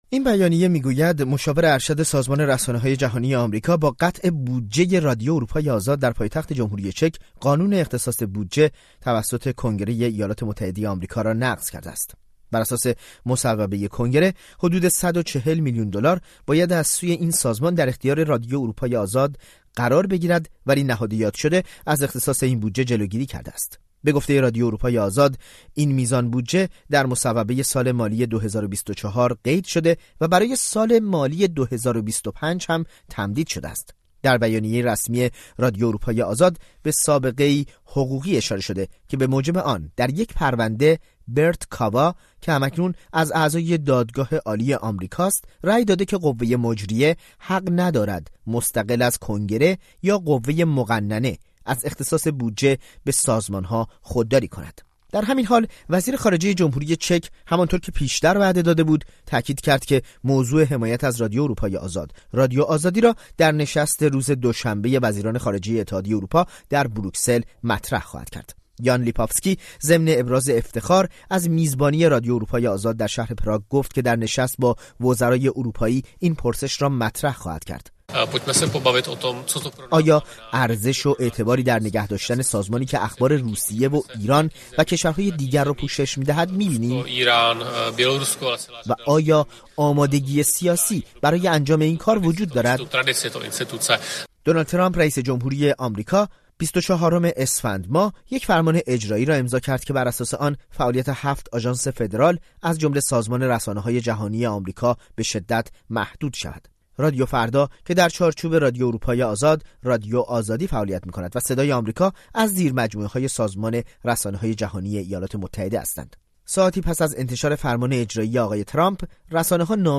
گزارش رادیویی در مورد قطع بودجه رادیو اروپای آزاد/ رادیو آزادی